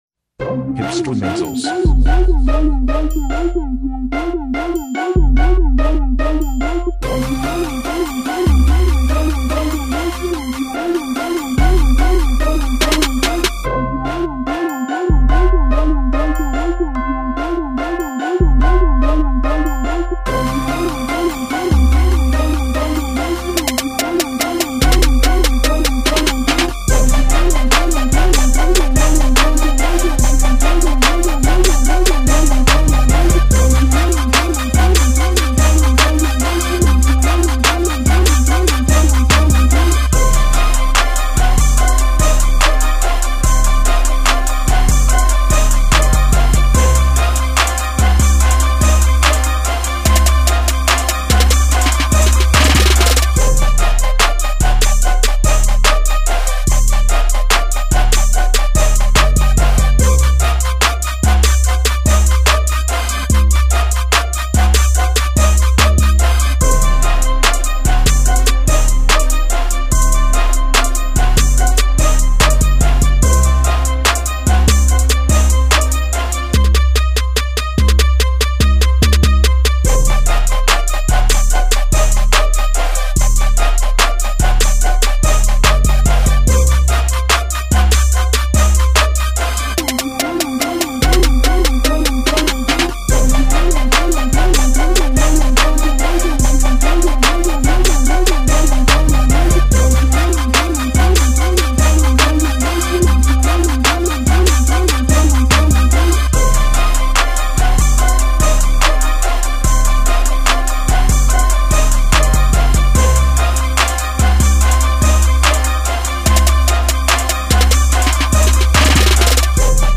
Snares, Hits, Claps, Pianos,